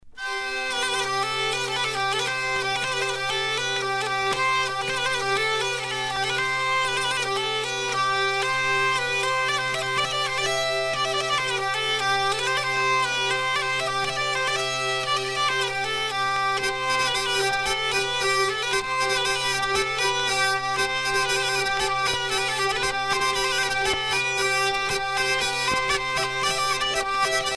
danse : rondeau
Genre laisse
Pièce musicale éditée